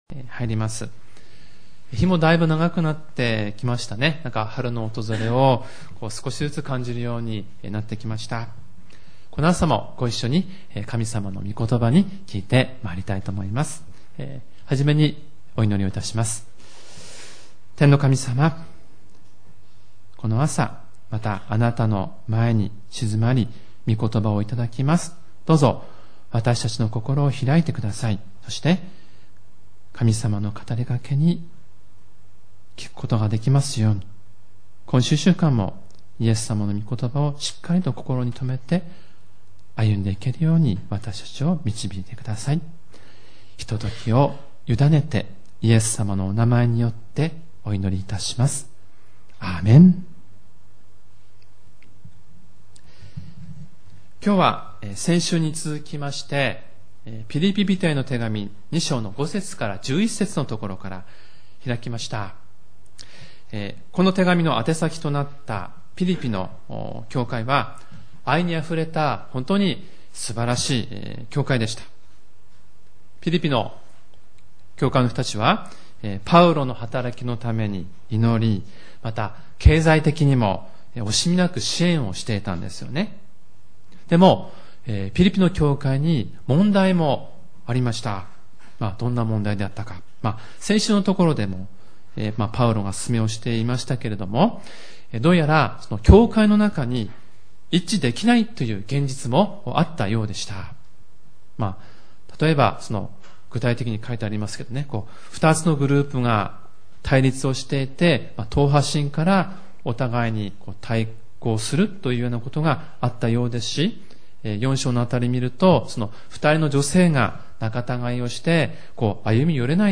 主日礼拝メッセージ